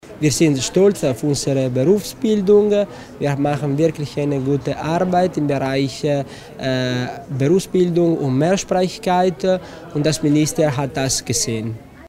Landesrat Tommasini zieht Bilanz über den Besuch des Ministers in Bozen